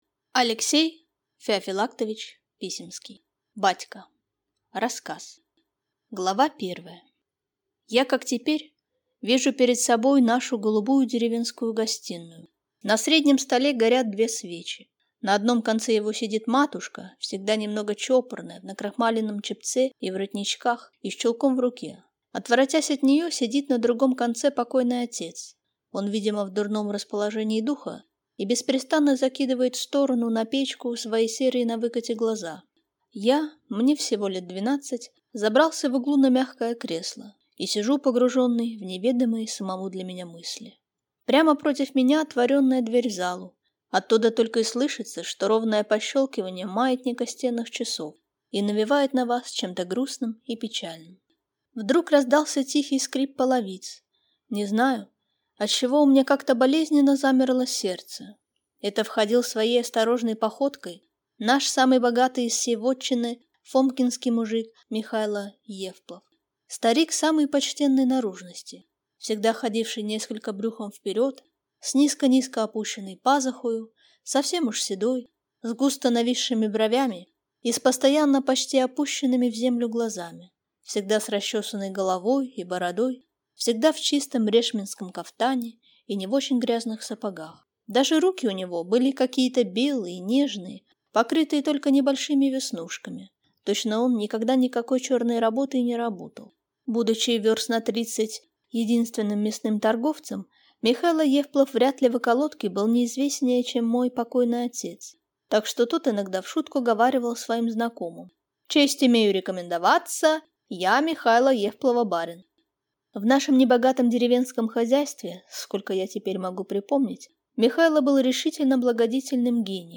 Аудиокнига Батька | Библиотека аудиокниг